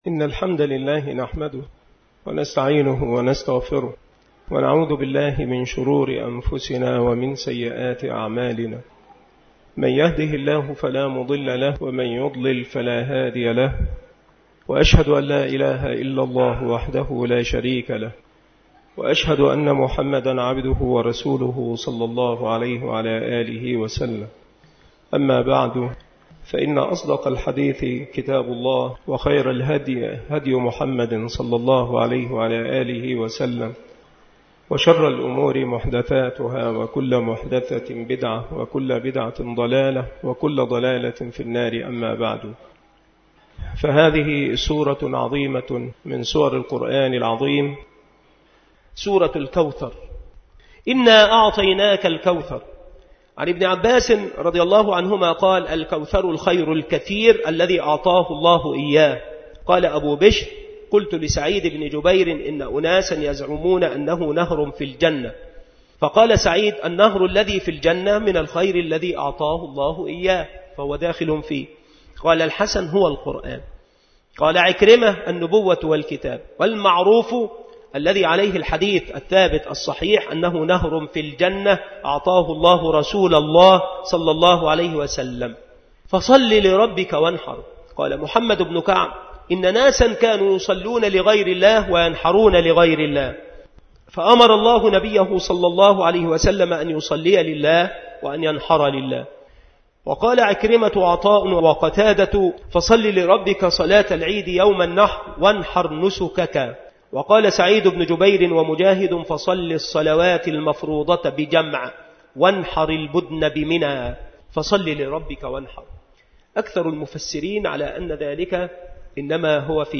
التصنيف التفسير
مكان إلقاء هذه المحاضرة بالمسجد الشرقي بسبك الأحد - أشمون - محافظة المنوفية - مصر